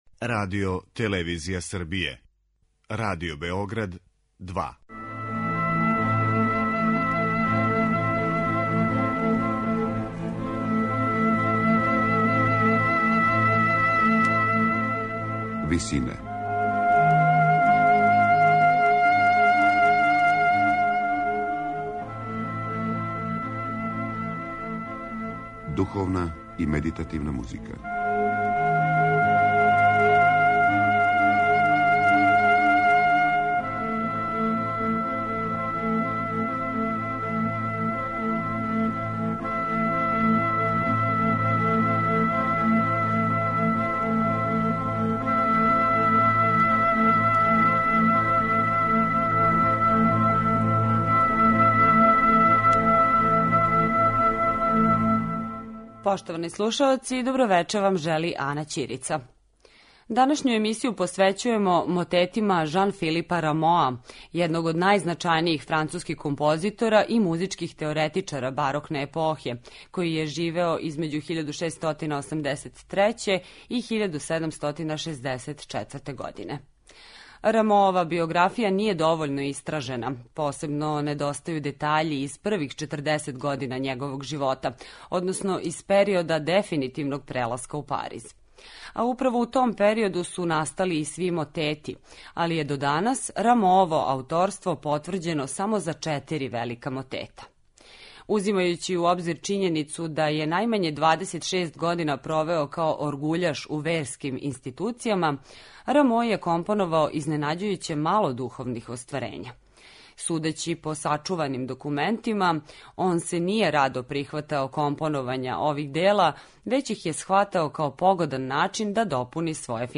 Псалма за четворо вокалних солиста , четворогласни и петогласни хор и оркестар, мотет In convertendo представља право ремек дело Рамоовог духовног опуса.